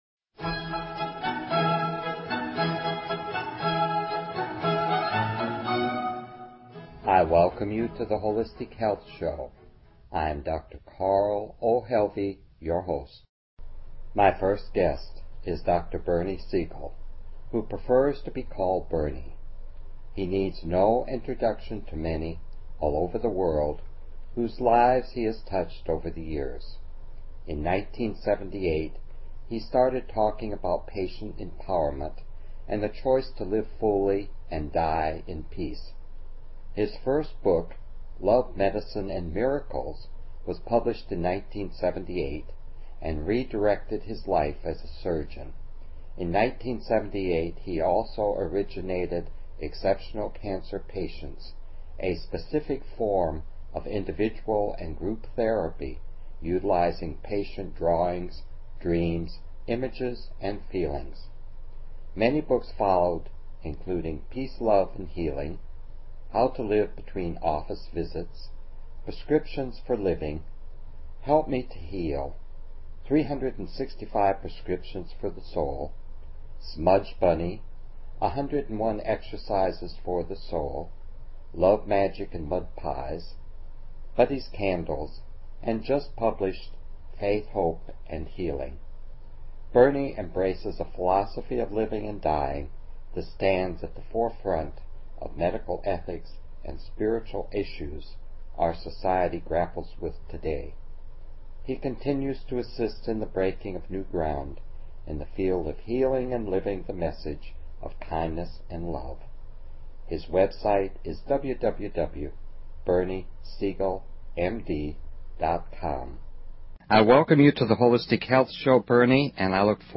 Talk Show Episode, Audio Podcast, The_Holistic_Health_Show and Courtesy of BBS Radio on , show guests , about , categorized as
Dr Bernie Siegel will be interviewed on the Holistic Health Show on BBS Radio Station 3 at 7 p.m. PST or 10 p.m. EST on Saturday, July 4 as part of the cancer series.